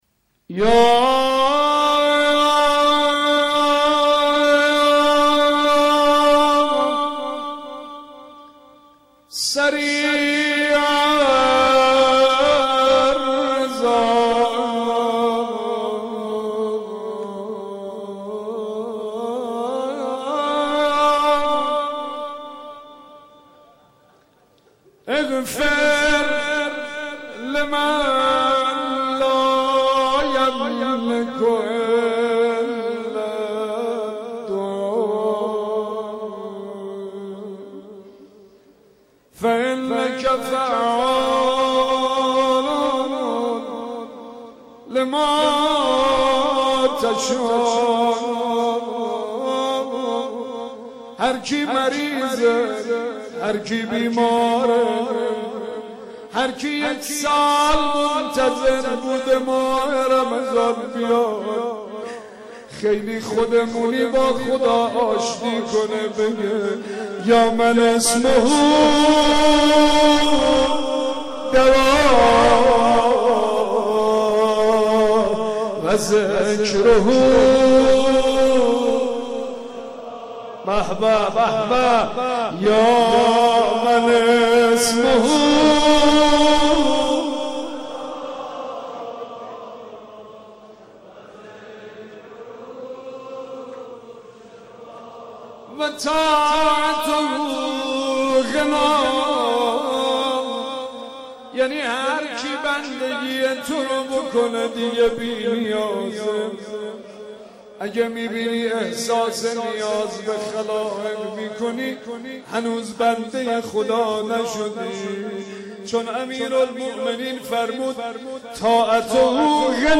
مناجات با خداوند و روضه خوانی
مناجات با خدا و روضه امام حسین علیه السلام.mp3